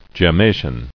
[gem·ma·tion]